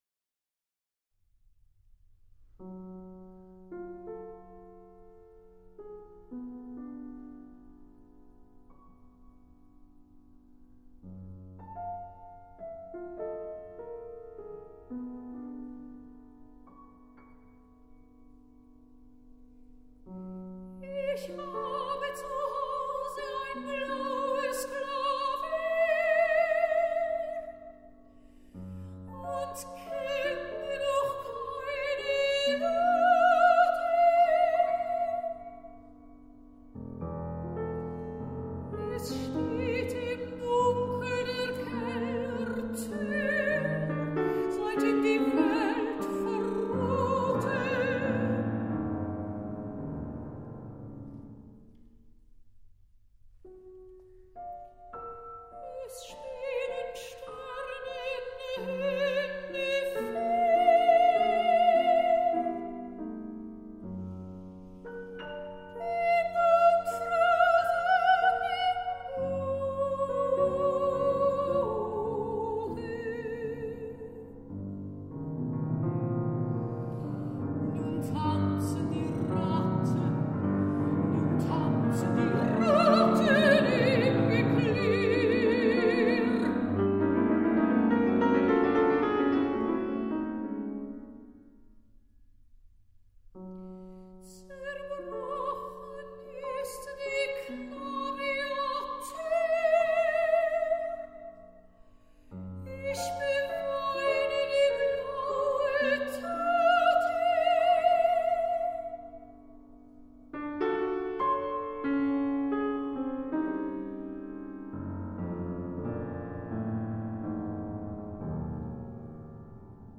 Songs by Swiss composers of the 20th century